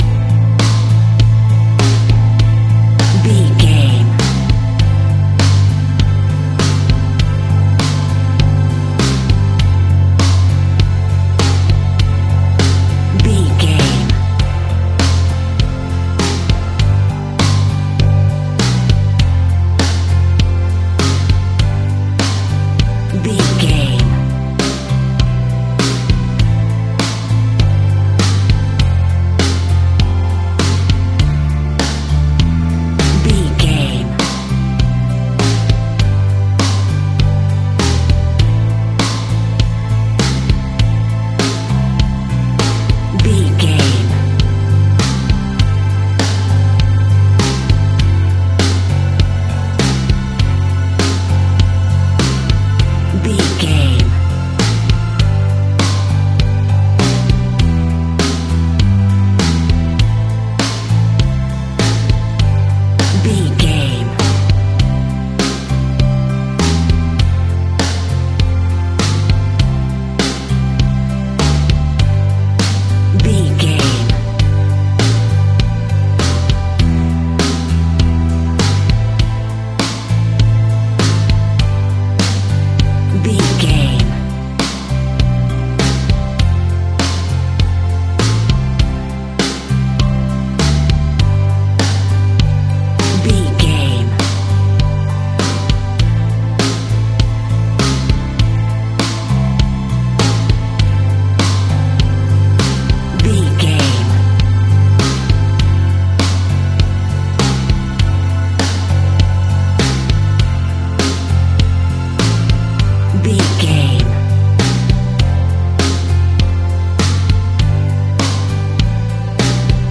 Ionian/Major
Slow
calm
melancholic
smooth
soft
uplifting
electric guitar
bass guitar
drums
indie pop
organ